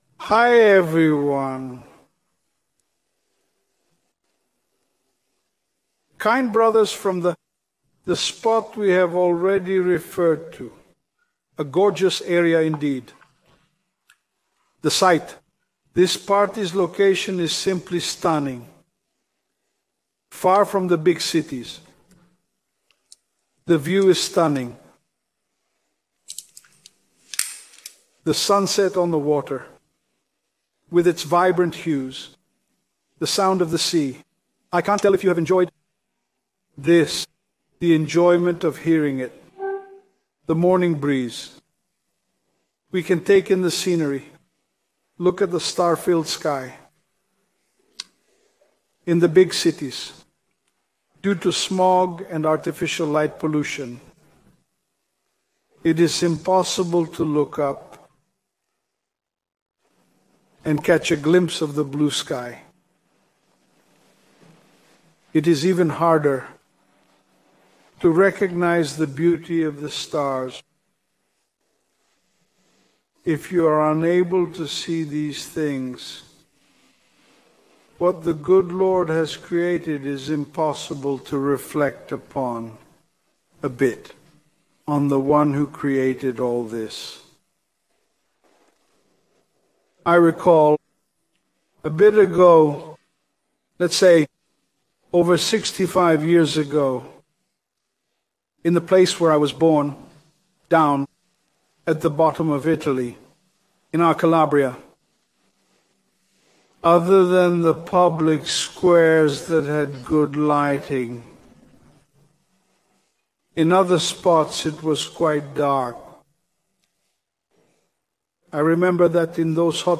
Sermons
FoT 2025 Sabaudia (Italy): 4th day